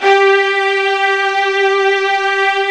55u-va07-G3.aif